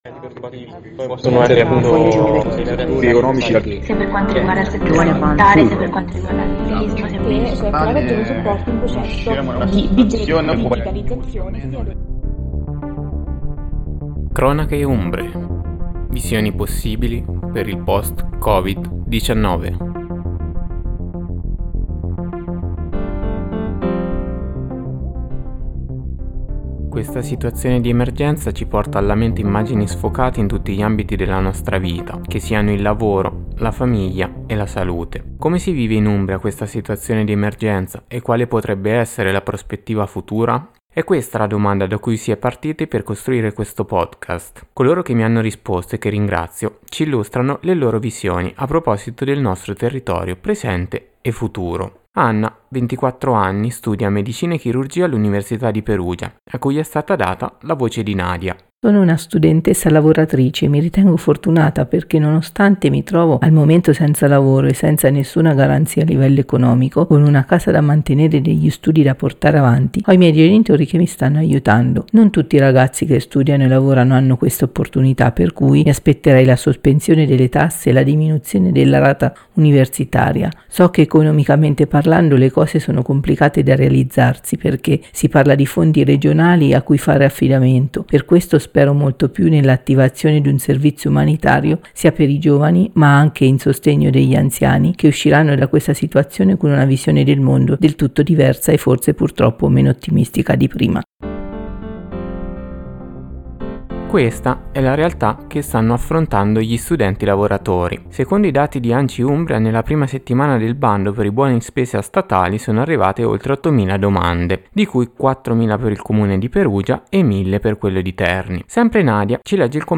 La domanda è: come si vive in Umbria questa situazione e quale potrebbe essere la prospettiva futura finito questo periodo di emergenza? Una “ricognizione d’ ascolto” in un gruppo di 23-32enni caratterizzati da un titolo d’istruzione elevato (laurea) e da una forte propensione ad affermarsi nei settori di maggior interesse.